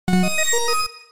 効果音のフリー素材です。
効果音5